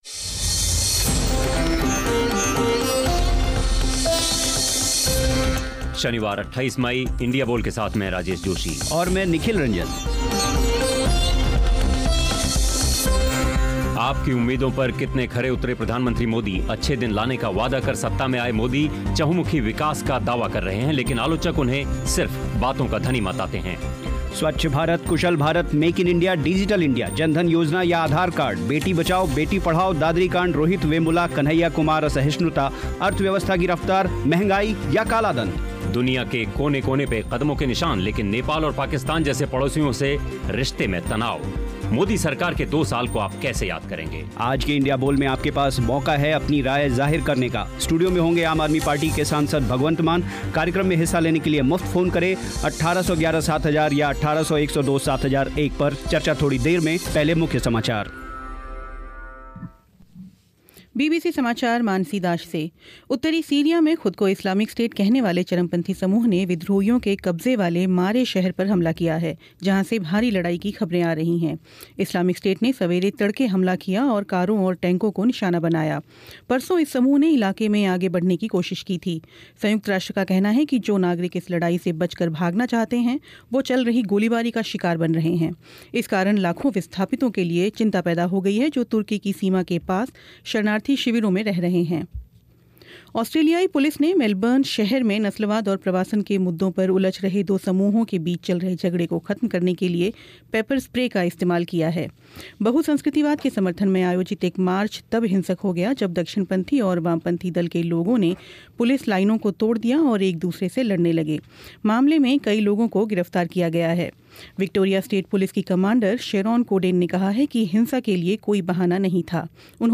नरेंद्र मोदी सरकार के दो साल उम्मीदों पर कितने खरे? बीबीसी इंडिया बोल में इसी विषय पर हुई चर्चा. स्टूडियो में मेहमान थे आम आदमी पार्टी के सांसद भगवंत मान.